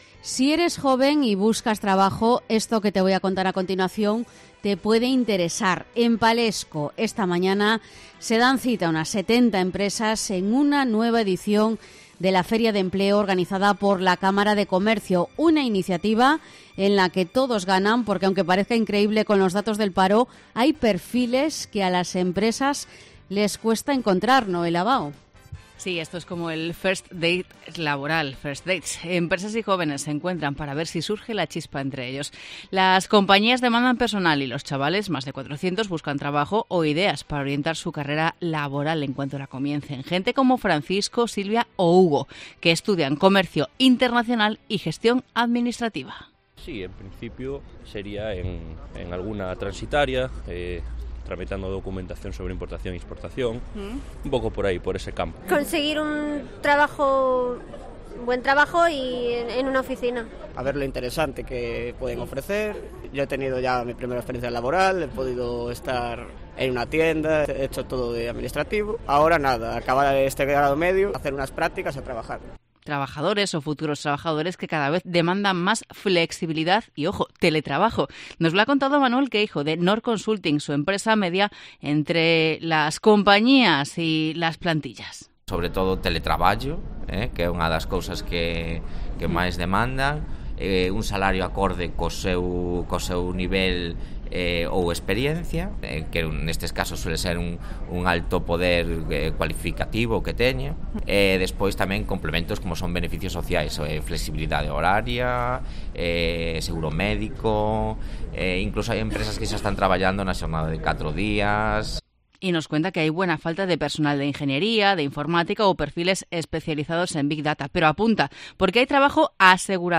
Crónica de la Feria de Empleo organizada por la Cámara de Comercio en Palexco